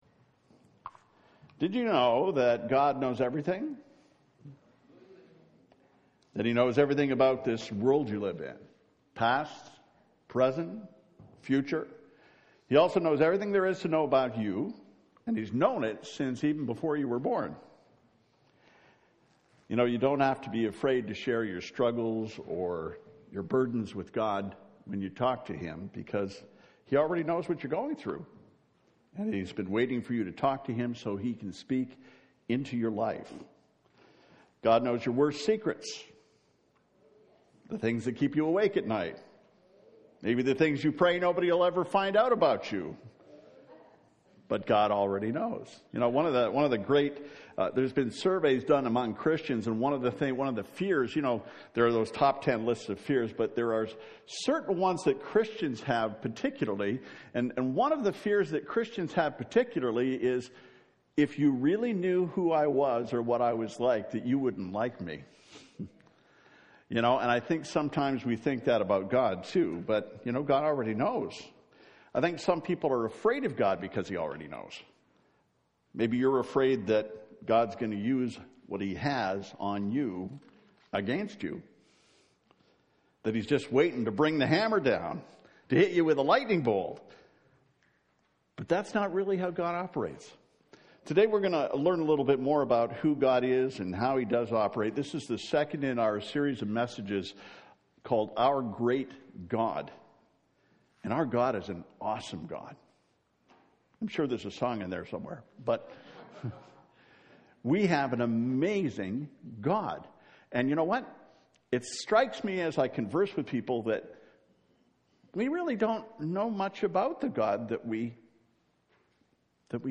04-Sermon-God-Omniscient.mp3